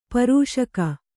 ♪ parūṣaka